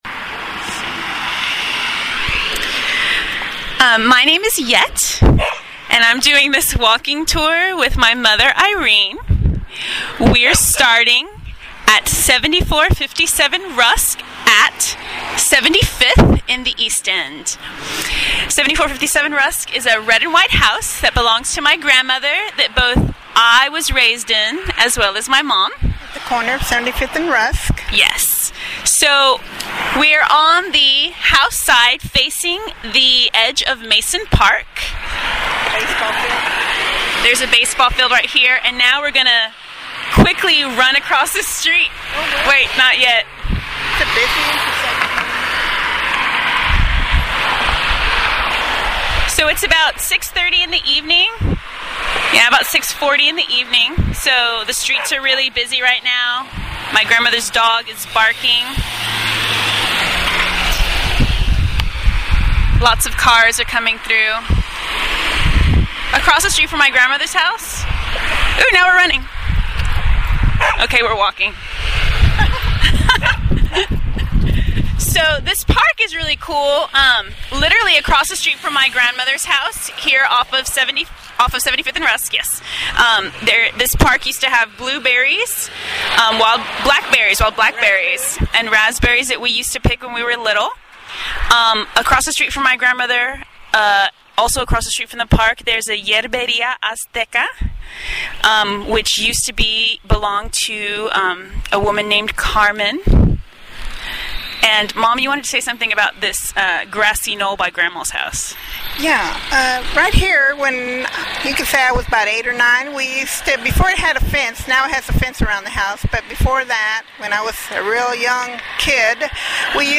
walking tour